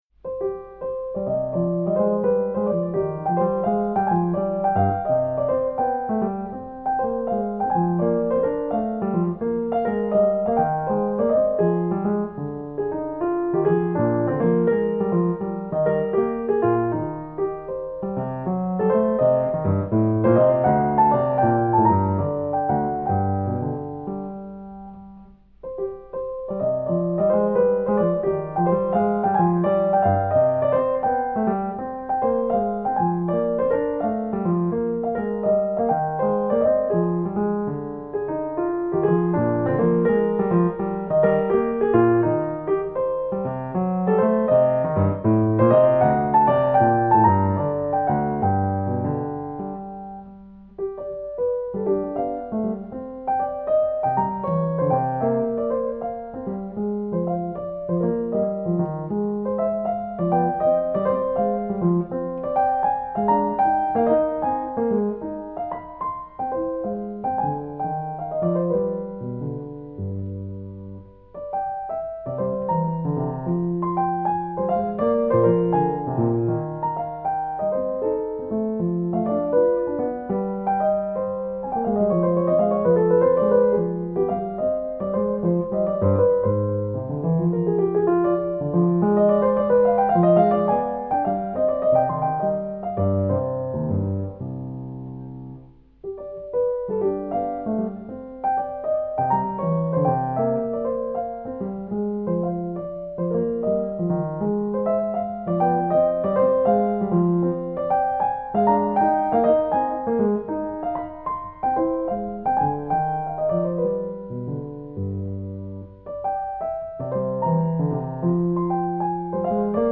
J.S.Bach_French_Suite_BWV_813_C_Minor_6_Gigue.mp3